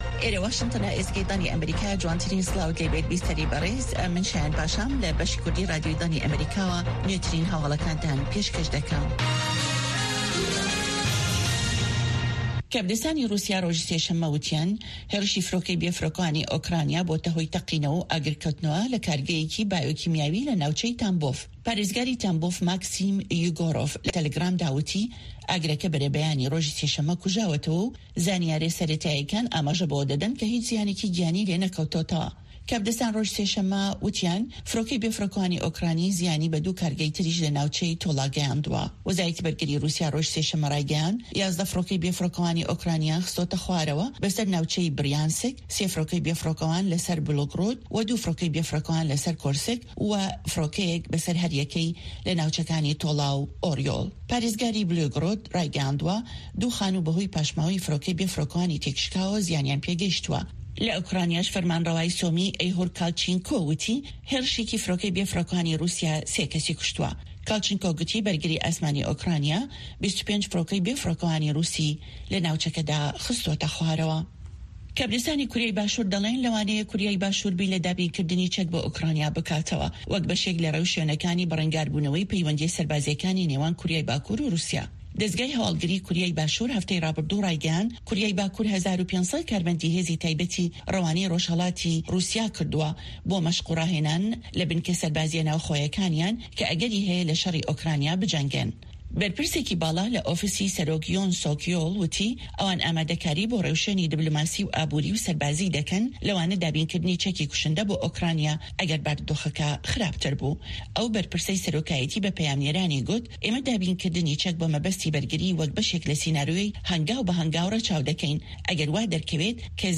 Nûçeyên 1’ê paşnîvro